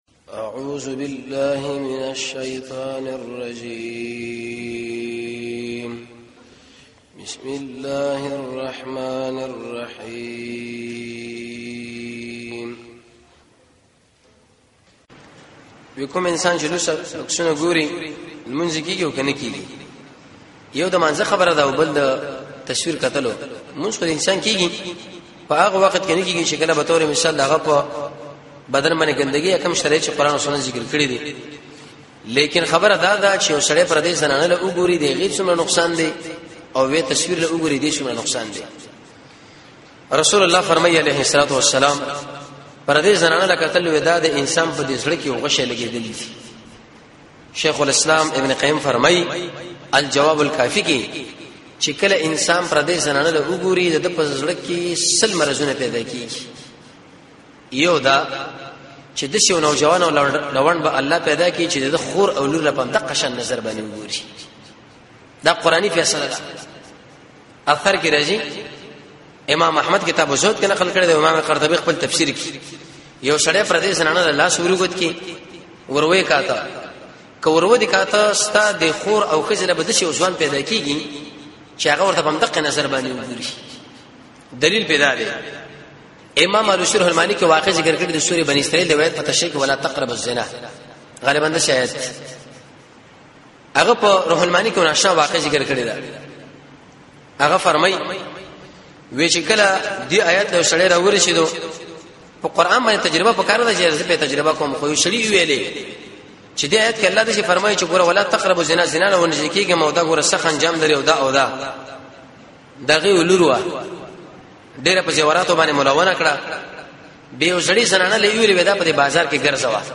۴ - پوښتنه او ځواب